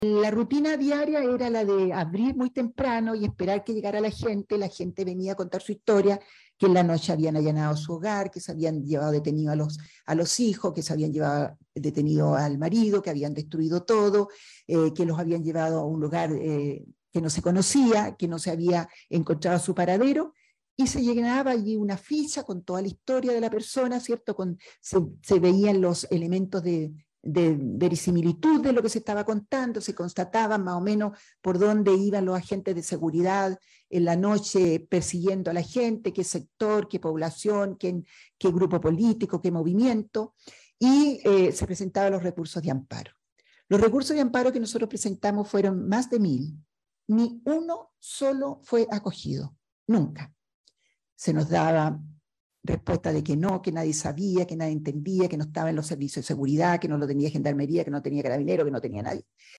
Martita Wörner Tapia, quien trabajó en esta institución como directora ejecutiva de la Pastoral de DD.HH del Arzobispado de Concepción, participó en un seminario organizado por el Departamento de Historia y Filosofía del Derecho UdeC, en el que abordó el rol de los abogados y abogadas de la Vicaría, durante la dictadura chilena.